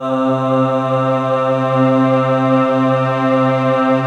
Index of /90_sSampleCDs/Optical Media International - Sonic Images Library/SI1_SlowOrchPad/SI1_SlowMelowPad